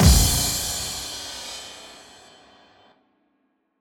Impact 09.wav